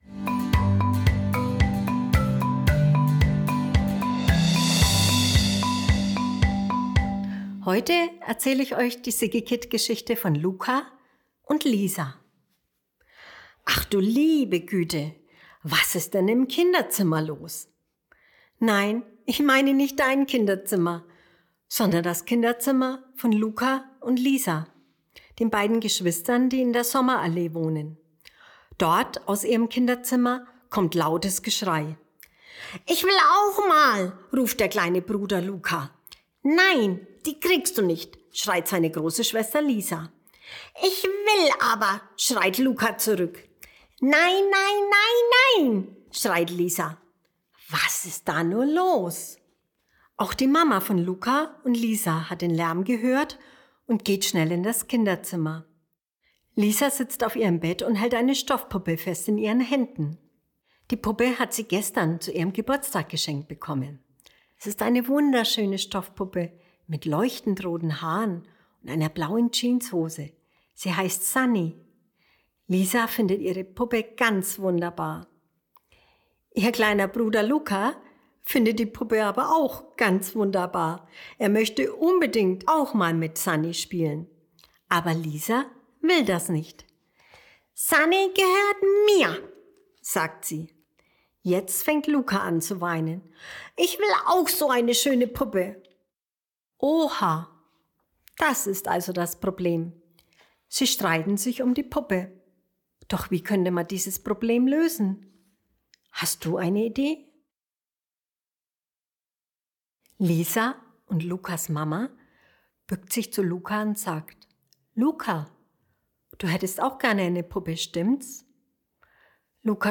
Eine liebevolle Vorlese- und Mitmachgeschichte über Geschwisterstreit und einer Bastelidee, die am Ende alle wieder vereint.